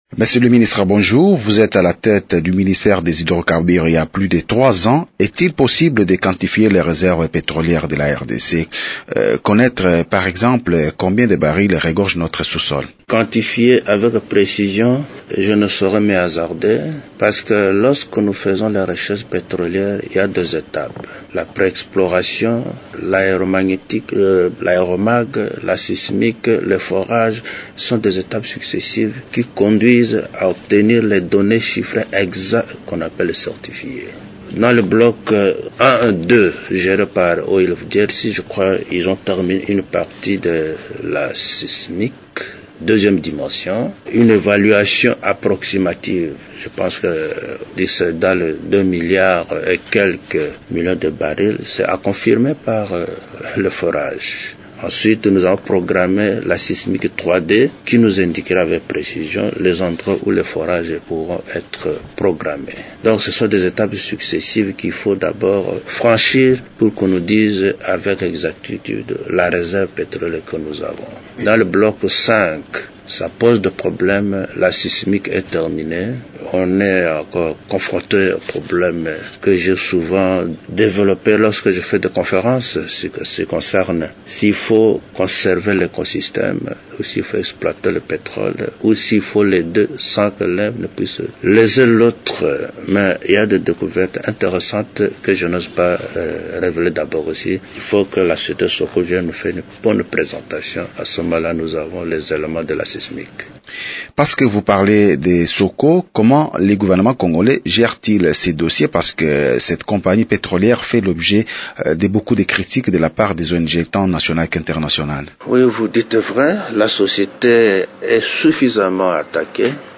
De la quantification des réserves pétrolières de la RDC à la polémique sur le projet de Soco d’explorer les gisements pétroliers dans le parc des Virunga en vue d’une éventuelle exploitation, le ministre congolais des Hydrocarbures, Crispin Atama s’exprime au micro de Radio Okapi.